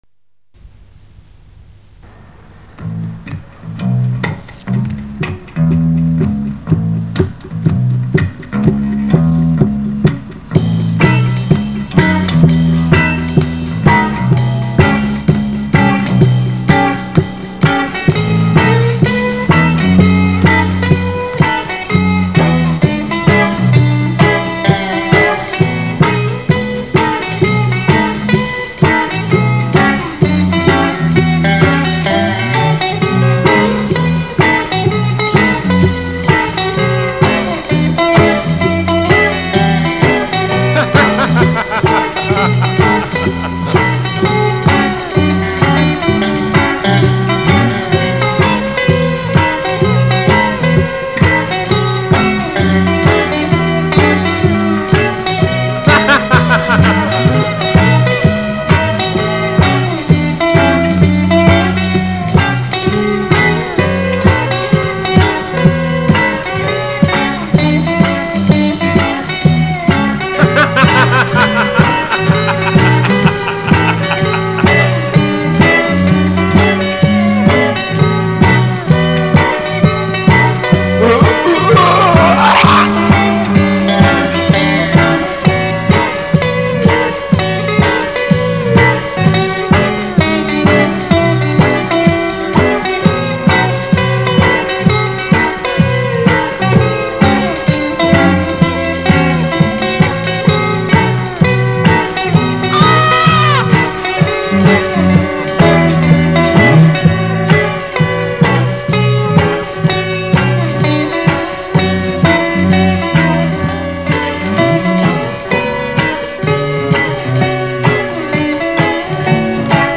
voice, bass, drums
lead guitar
rhythm guitar
organ.